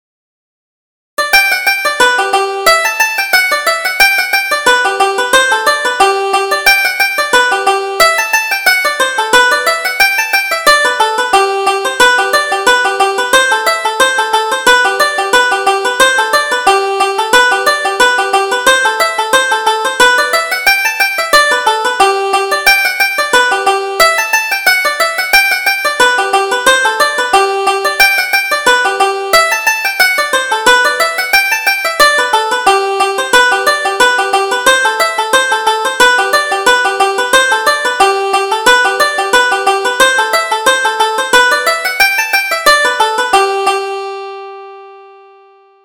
Reel: Captain Byng